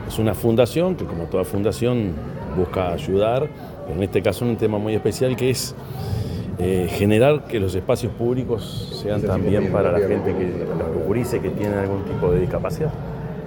Declaraciones del presidente de la República, Yamandú Orsi
El presidente de la República, Yamandú Orsi, participó en la gala por el décimo aniversario de la Fundación Jazmín, dedicada a promover la inclusión